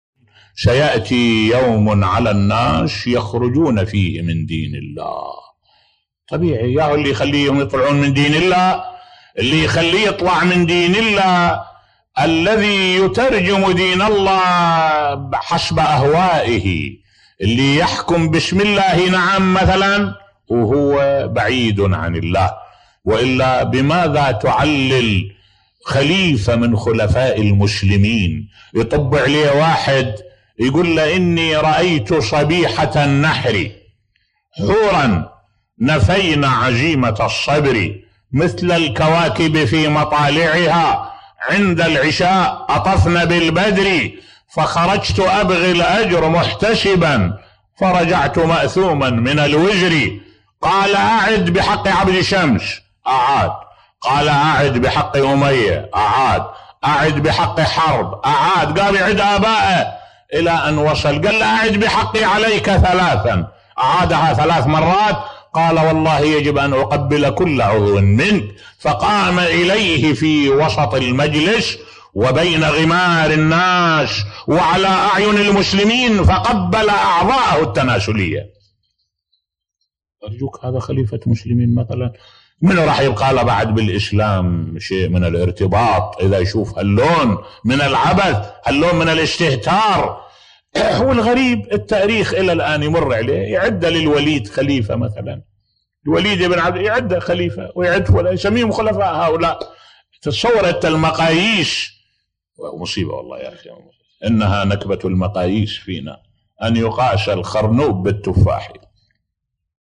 ملف صوتی سيأتي يوما على الناس يخرجون فيه من دين الله بصوت الشيخ الدكتور أحمد الوائلي